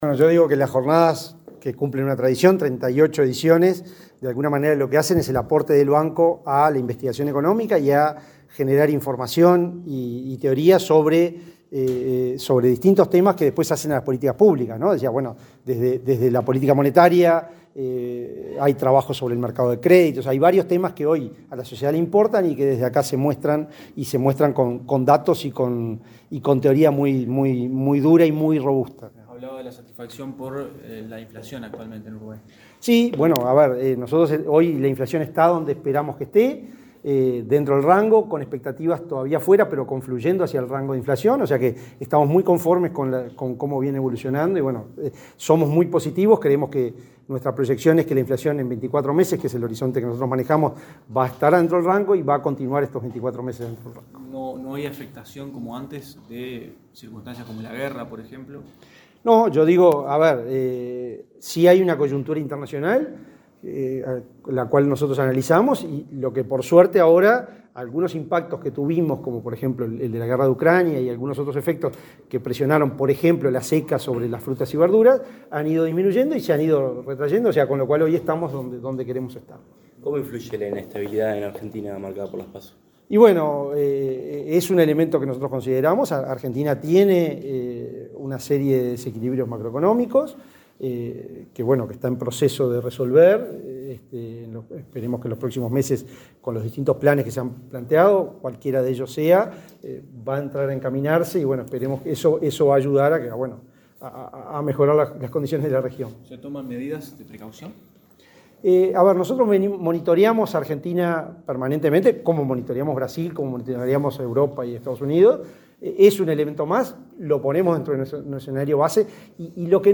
Declaraciones del presidente del BCU, Diego Labat
Declaraciones del presidente del BCU, Diego Labat 22/08/2023 Compartir Facebook X Copiar enlace WhatsApp LinkedIn El presidente del Banco Central del Uruguay (BCU), Diego Labat, dialogó con la prensa luego de realizar la apertura de las Jornadas Anuales de Economía, que se llevan a cabo entre este 22 y 23 de agosto en esa institución.